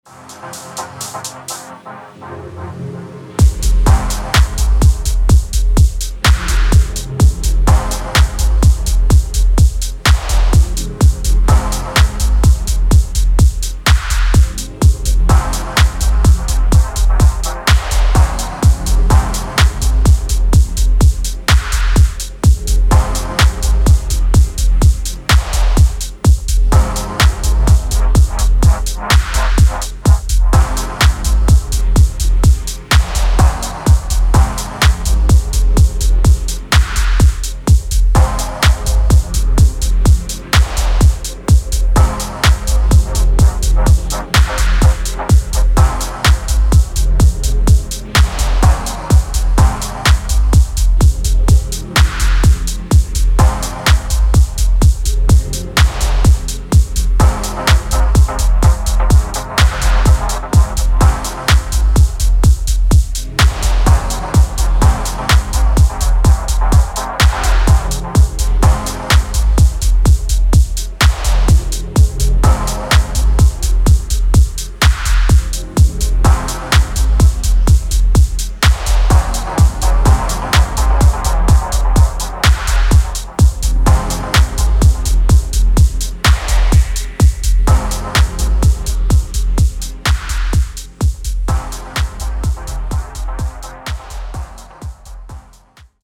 B面はインストダブで、こちらはステッパーズで四つ打ち感覚を押し出したバッチリ使えるテクノです。